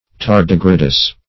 Search Result for " tardigradous" : The Collaborative International Dictionary of English v.0.48: Tardigradous \Tar"di*gra`dous\, a. Moving slowly; slow-paced.
tardigradous.mp3